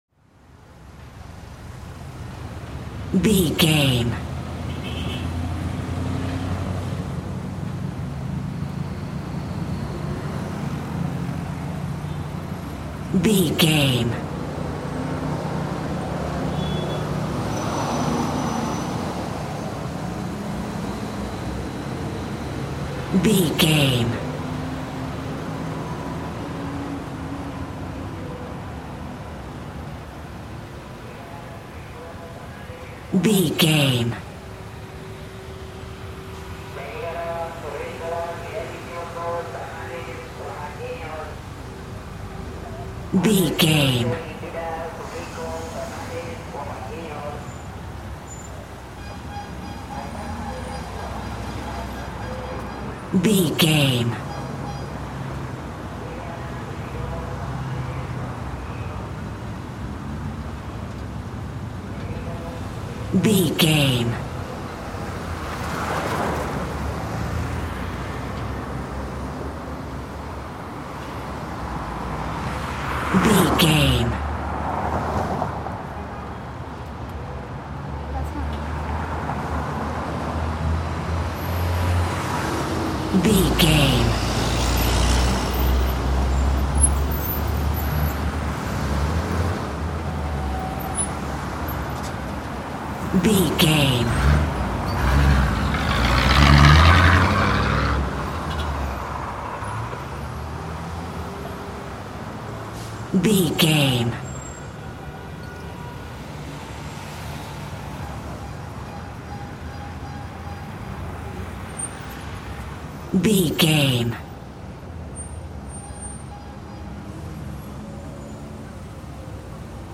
City street traffic
Sound Effects
urban
chaotic
ambience